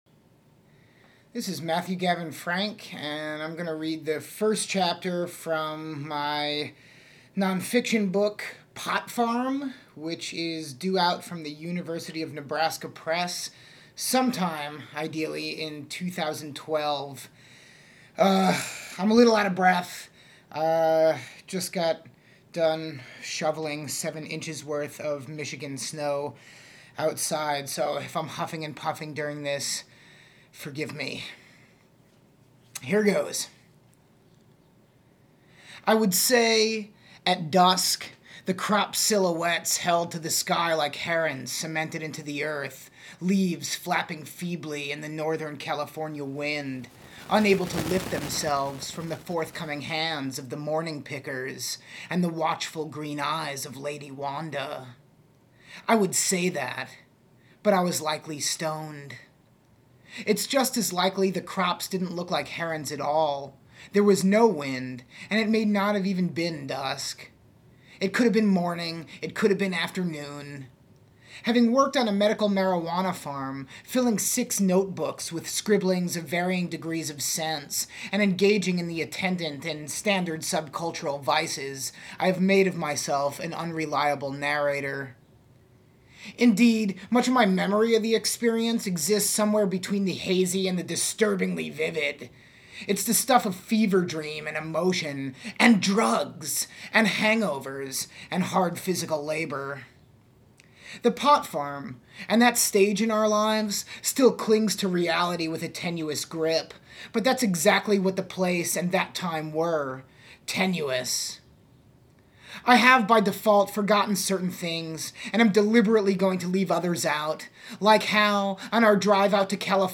reads the first chapter